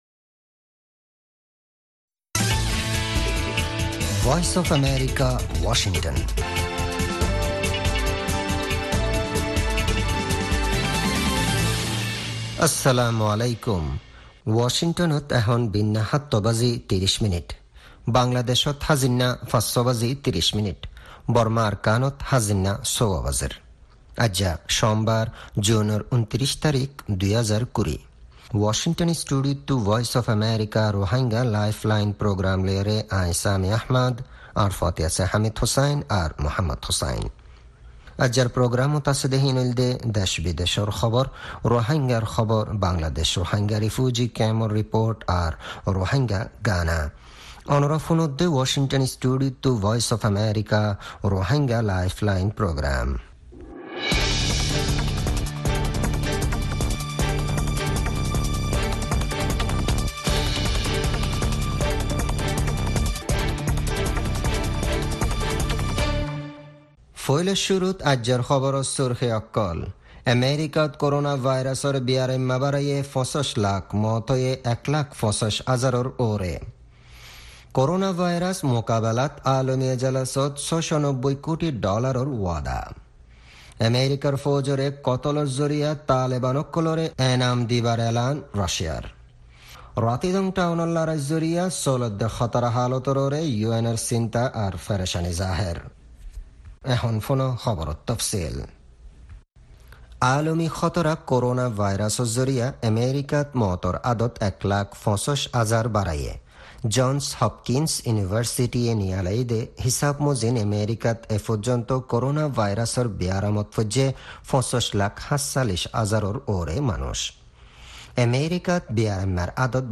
News Headlines
Shortwave: 31-meter band, 9350 kHz; 25-meter band, 11700 kHz and 12030 kHz Medium wave (AM): 1575 kHz